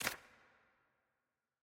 opal-sfx-click-temp.ogg